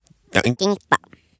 これはトンイルの3次元の姿とトンイルくんの声だ！